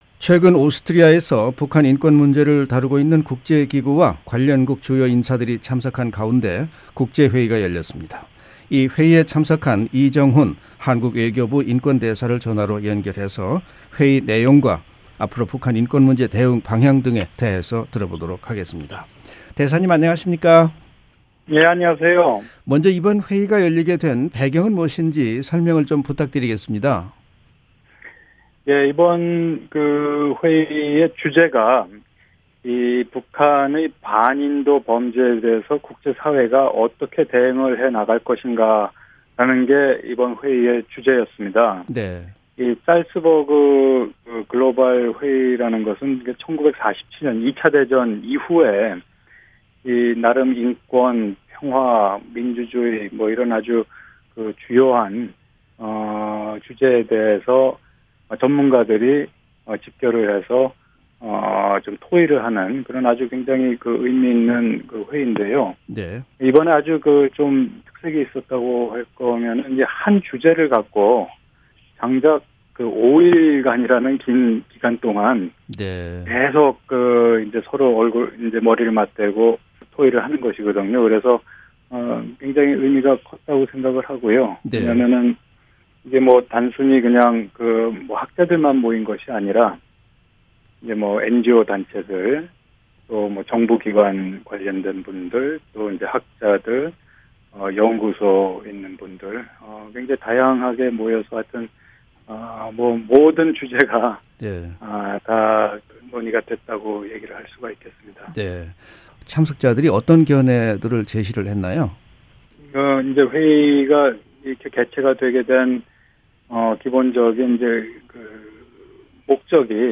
[인터뷰] 이정훈 한국 외교부 인권대사